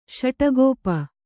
♪ śaṭagōpa